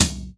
TOM     4A.wav